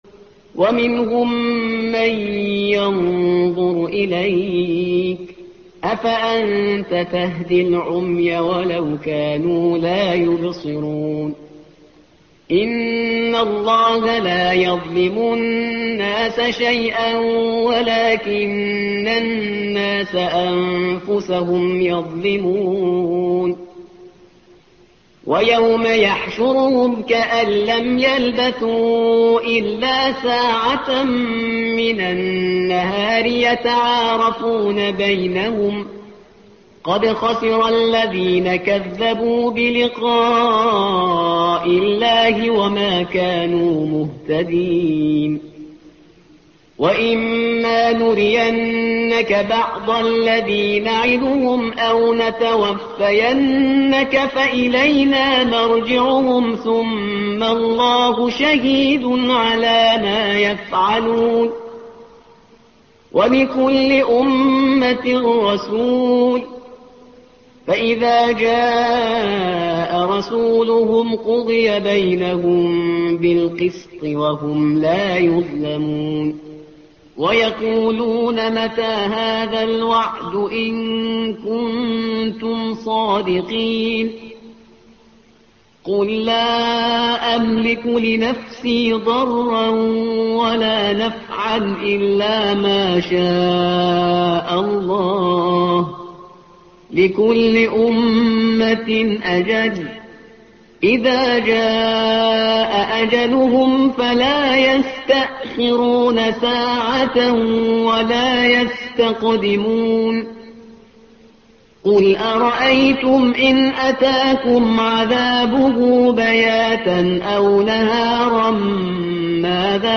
الصفحة رقم 214 / القارئ